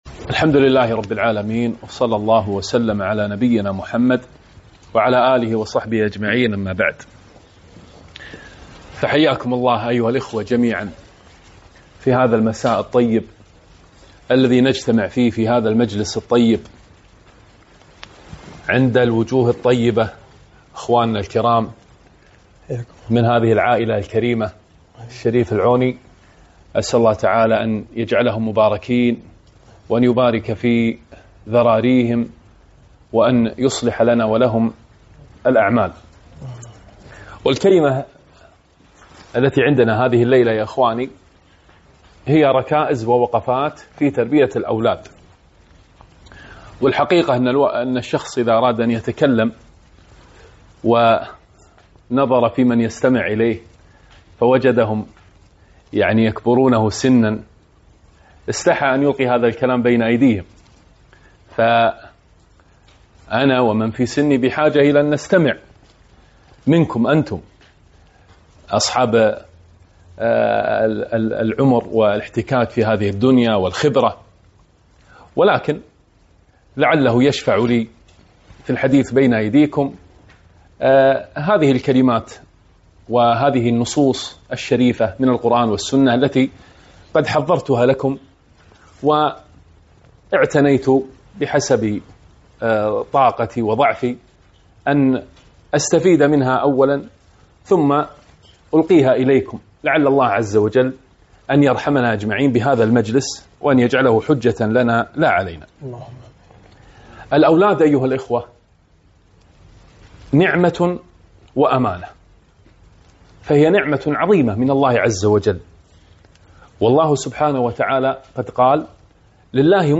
محاضرة - وقفات مع تربية الأولاد